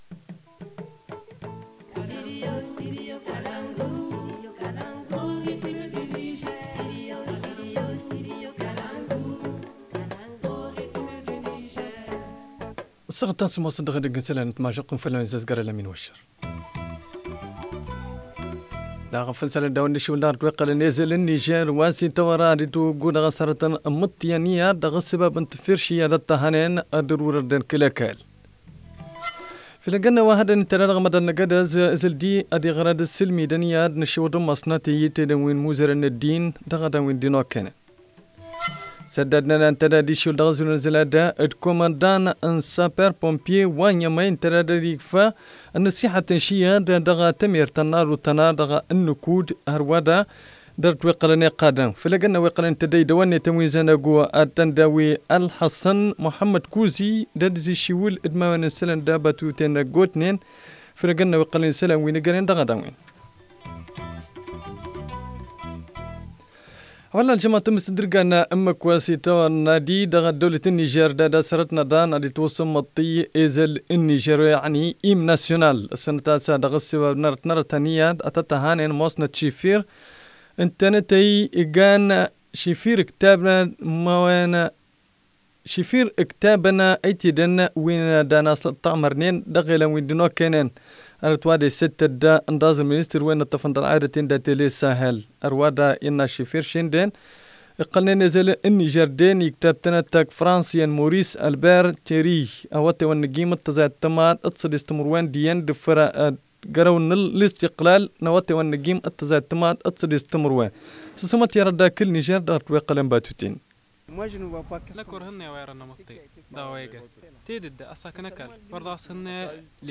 Le journal du 22 novembre 2019 - Studio Kalangou - Au rythme du Niger